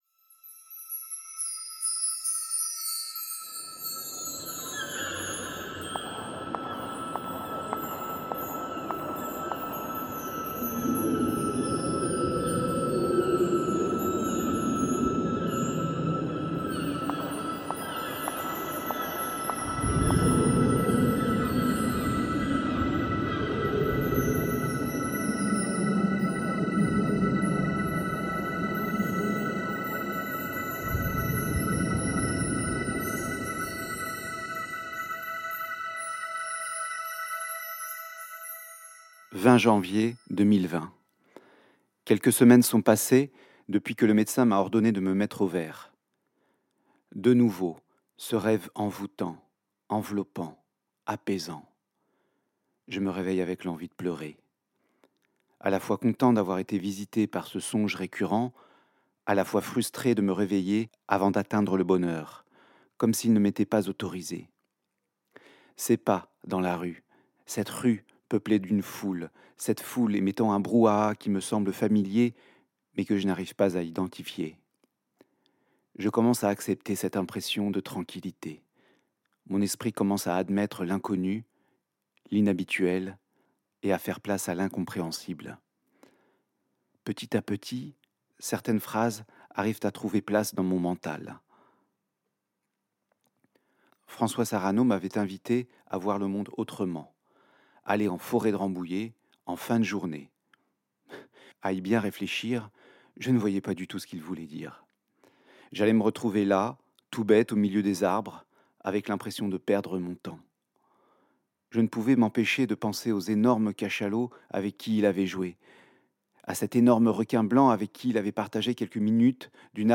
Un dialogue s'installe entre Céline Cousteau, qui a vécu l'aventure Cousteau de l'intérieur, en famille, et le personnage principal.
Deux personnes d’une même génération.